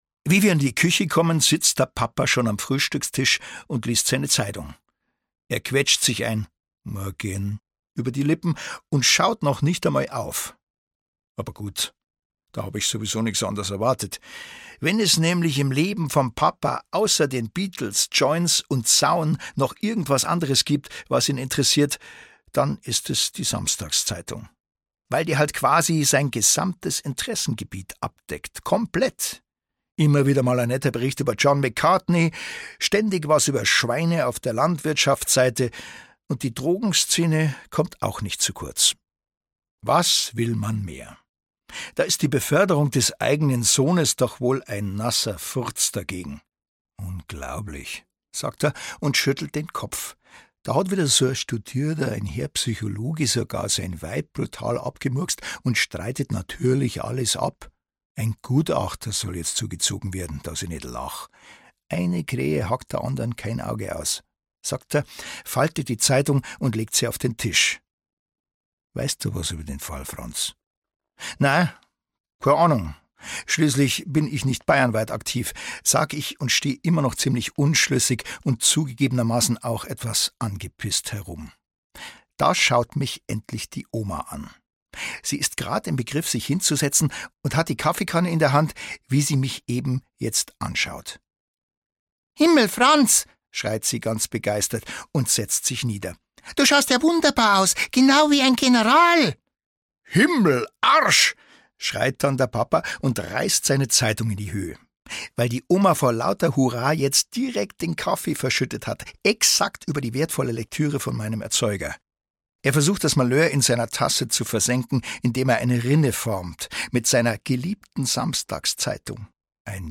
Schweinskopf al dente - Rita Falk - Hörbuch